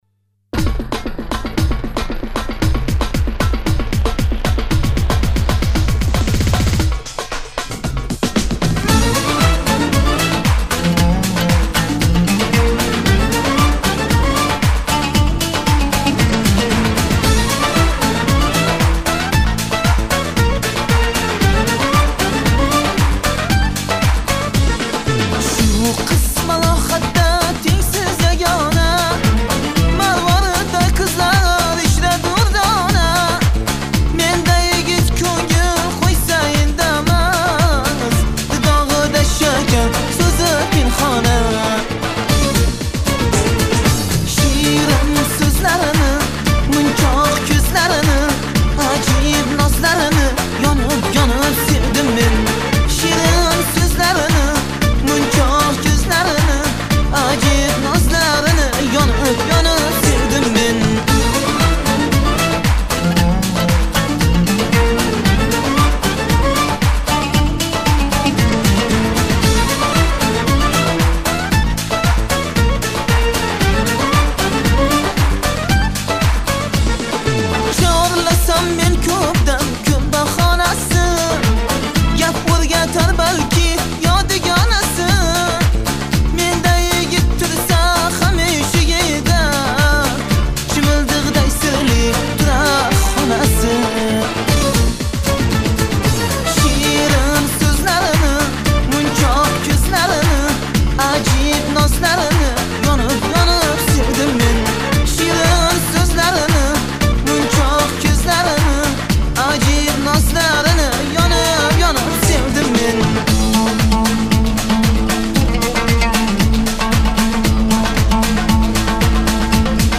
Узбекская музыка